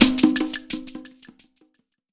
TC2 Perc12.wav